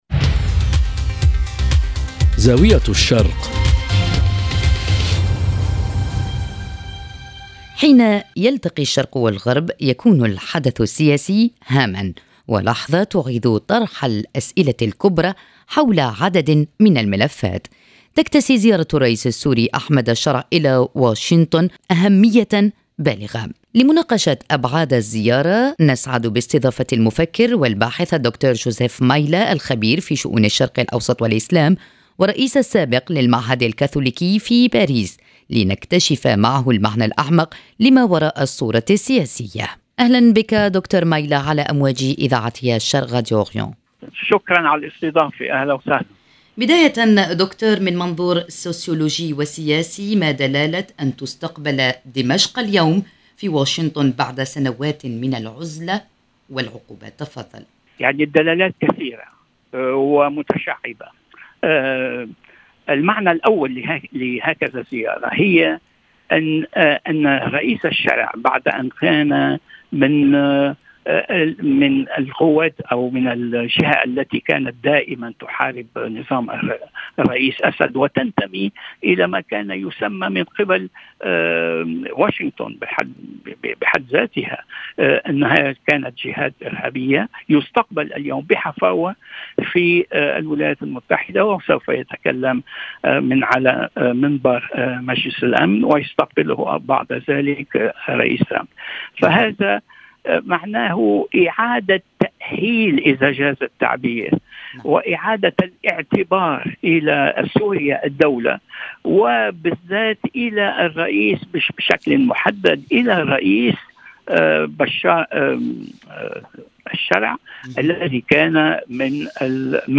في حوارٍ يتناول الدلالات الفكرية والتحولات العميقة التي تعكسها عودة دمشق إلى الساحة الدولية